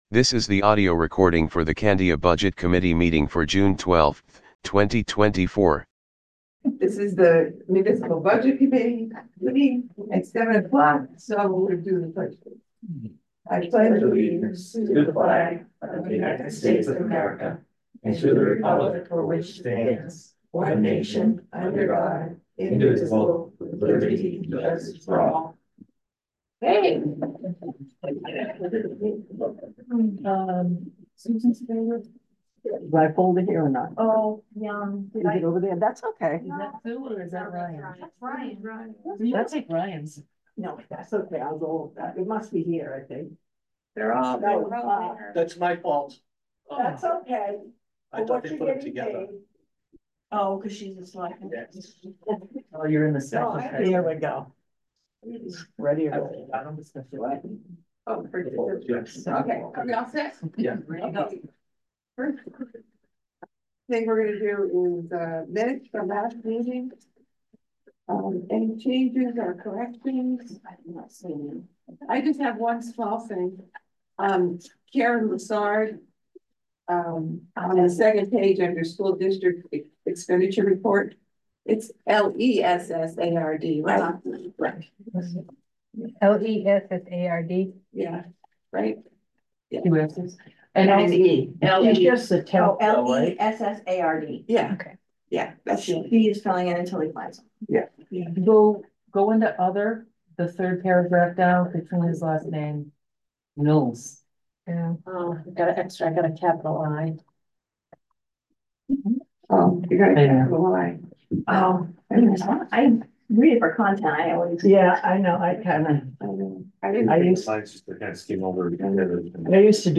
Audio recordings of committee and board meetings.
Budget Committee Meeting